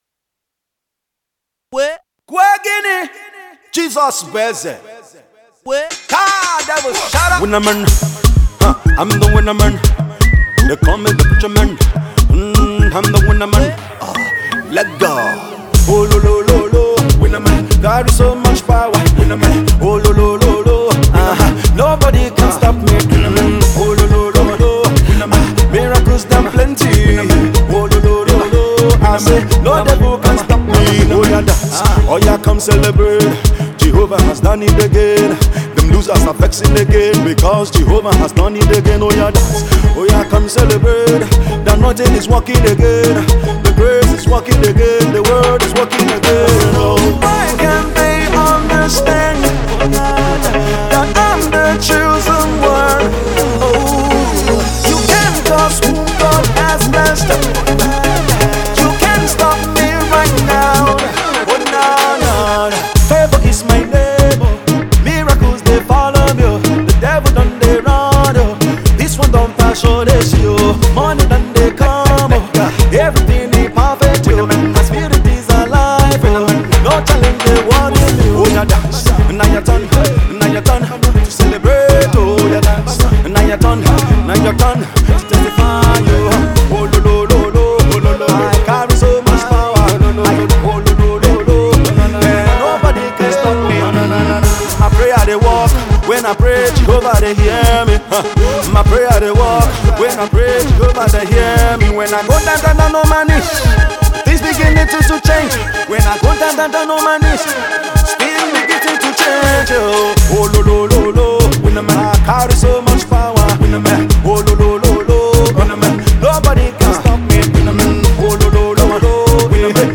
gospel
is an energetic track that will get you to dance
is a mid tempo praise song